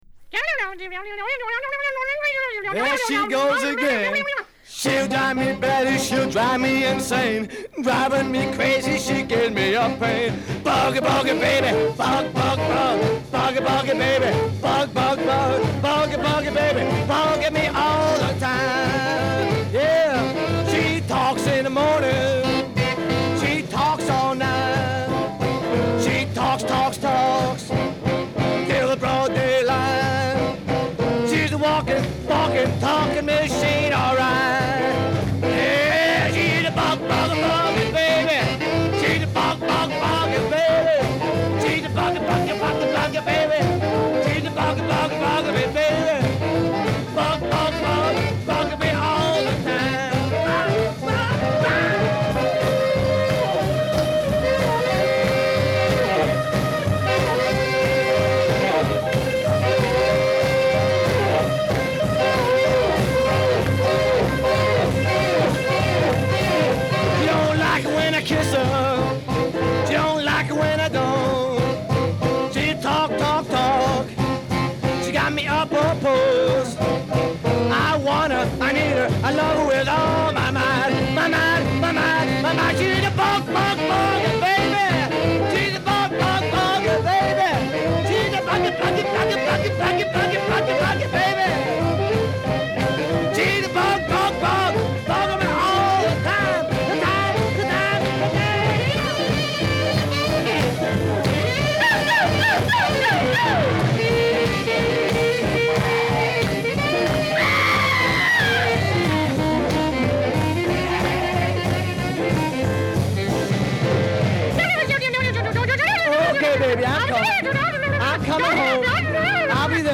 吐き捨てるようなダミ声ヴォーカルにスクリーム、奇声までたっぷり盛り込んだディスパレイト・ロックンロールの最高峰。